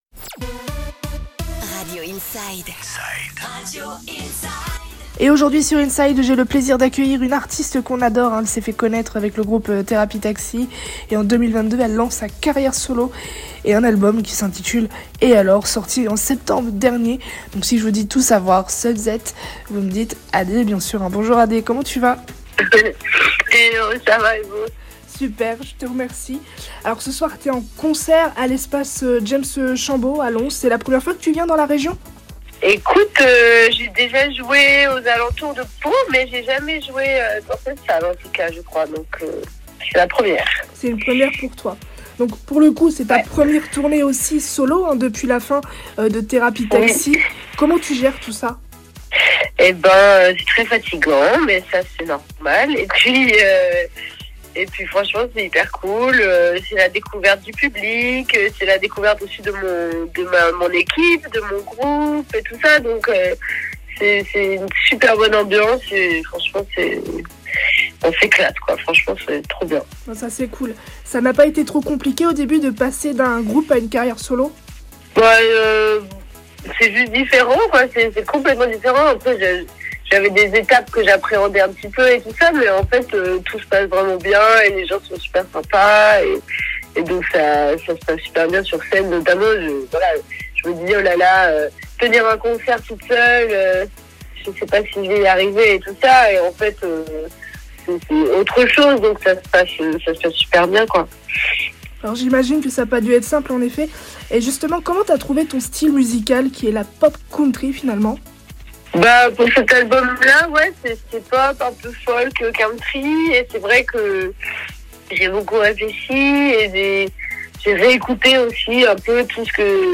Interview d'Adé à l'occasion de son concert à l'Espace James Chambaud sur Radio Inside